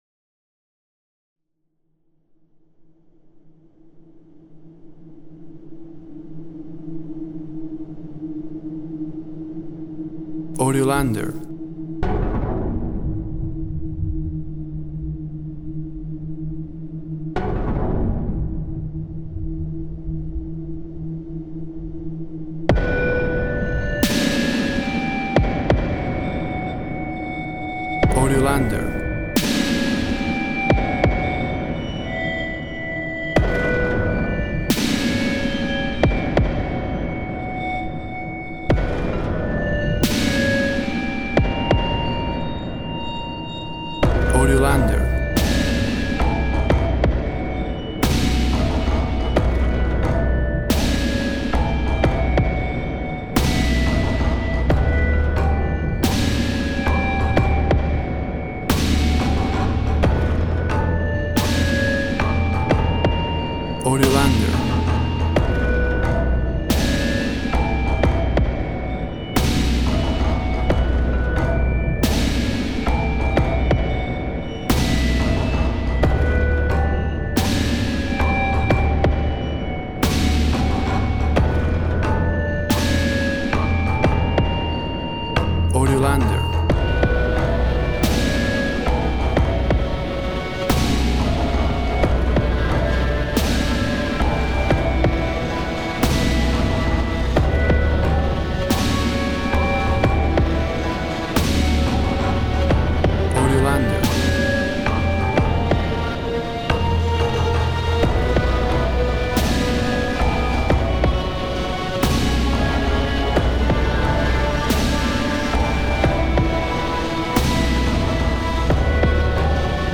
WAV Sample Rate 24-Bit Stereo, 44.1 kHz
Tempo (BPM) 70